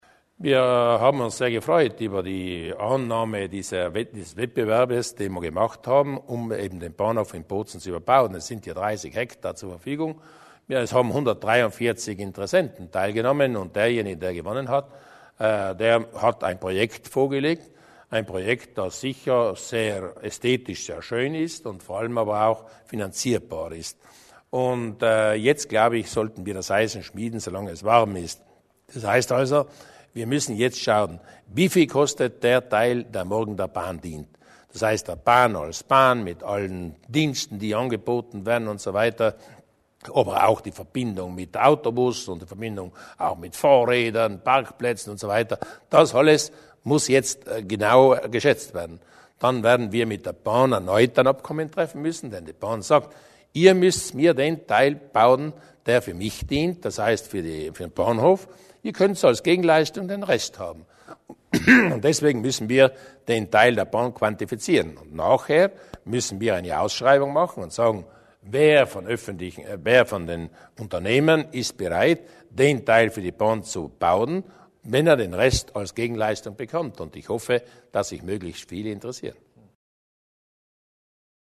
Landeshauptmann Durnwalder zum Projekt für das Bozner Bahnhofsareal